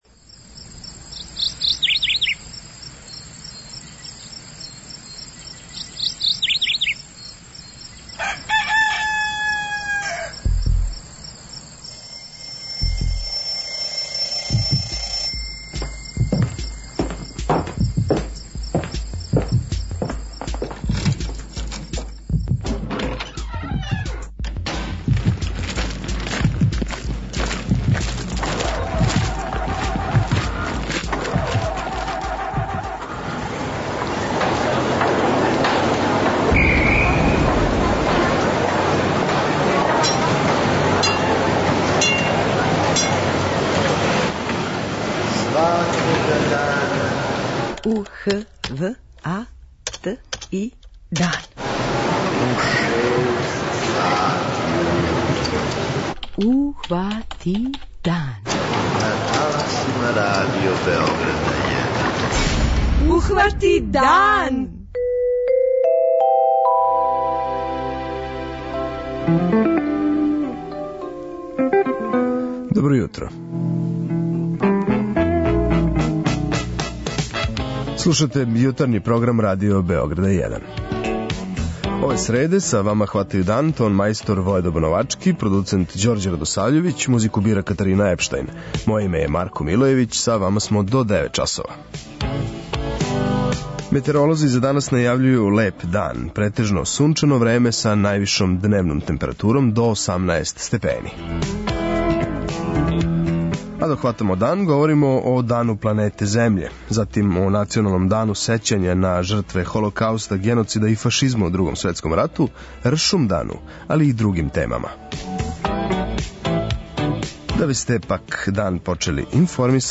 преузми : 43.15 MB Ухвати дан Autor: Група аутора Јутарњи програм Радио Београда 1!